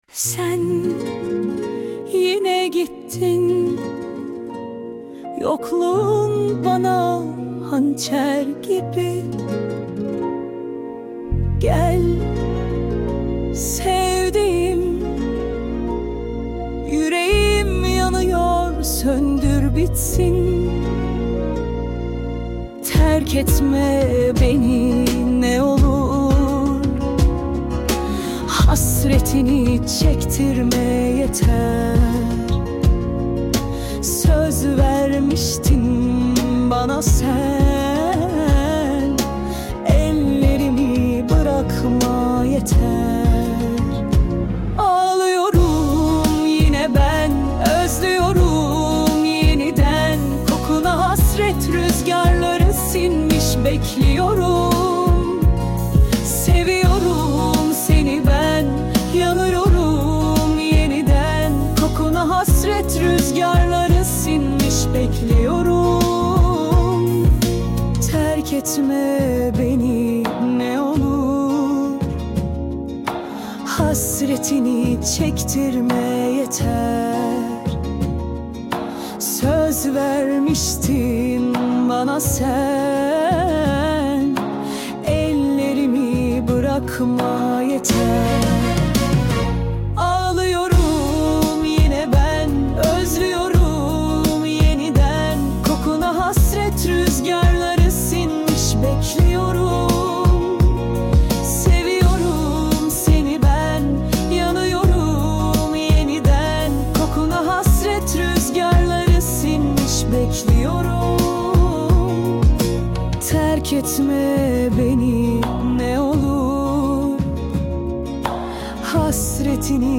Tür : Akdeniz, Melankolik, Pop